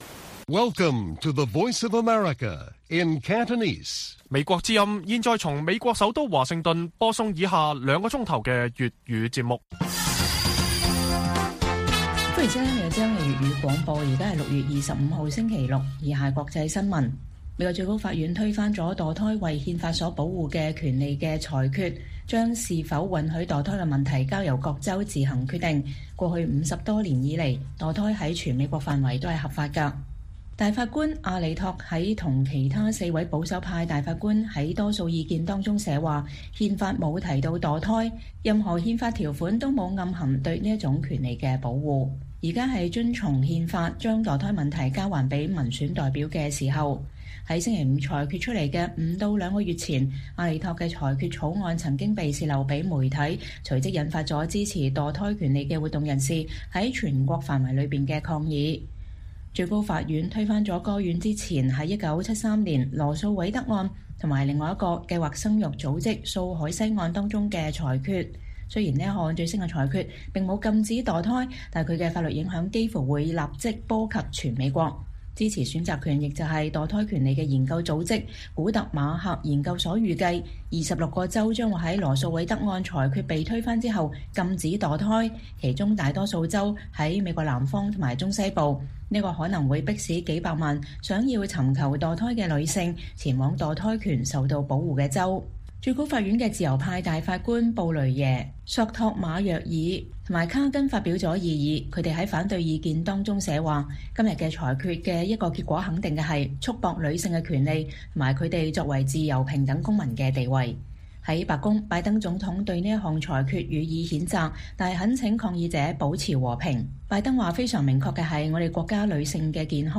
粵語新聞 晚上9-10點：美最高法院推翻保護墮胎權判例